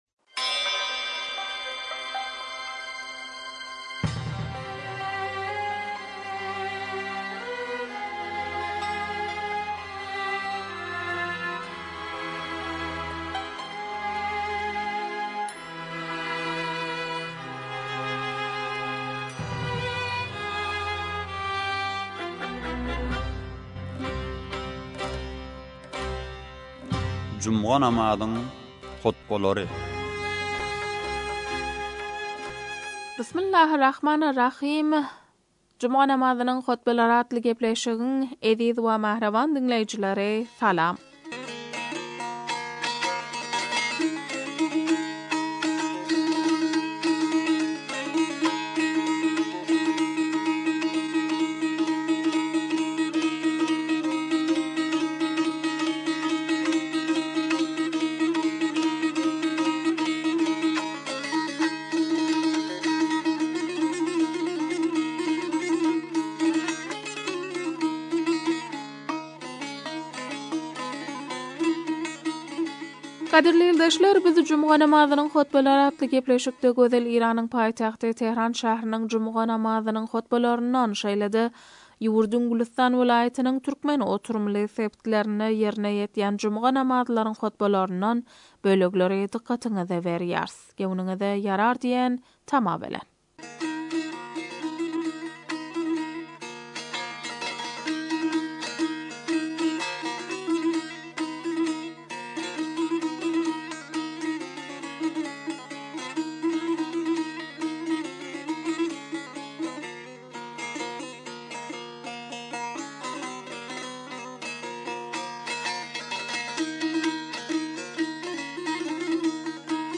juma namazyň hutbalary